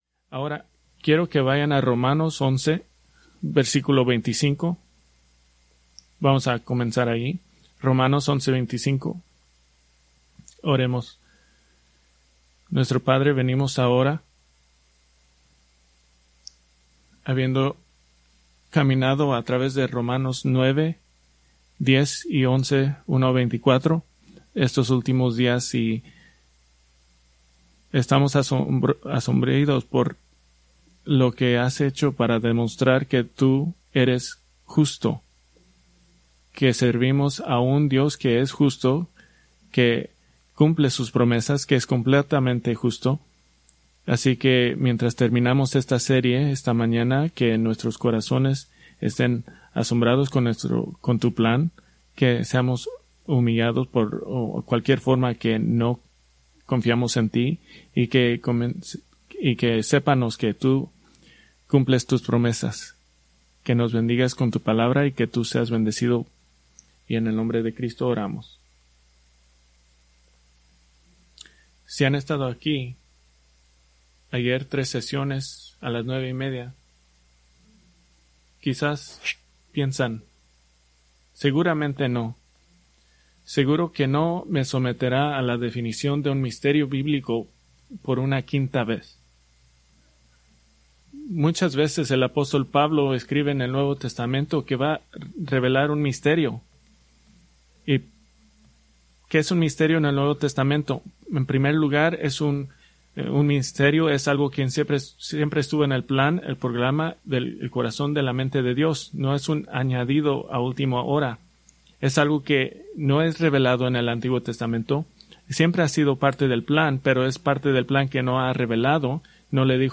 Preached April 19, 2026 from Romanos 11:25-36